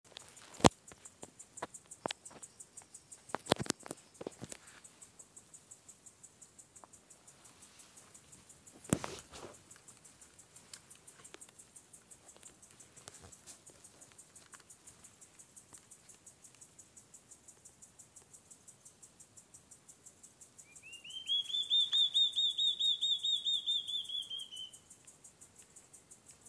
Large-tailed Antshrike (Mackenziaena leachii)
Sex: Male
Life Stage: Adult
Location or protected area: Parque Provincial Caá Yarí
Condition: Wild
Certainty: Observed, Recorded vocal